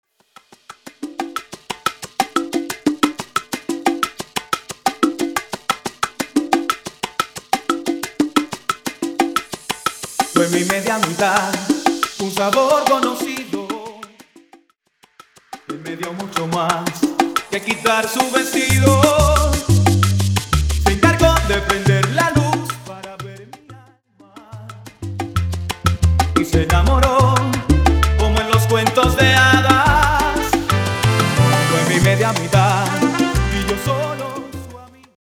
Intro Acapella Dirty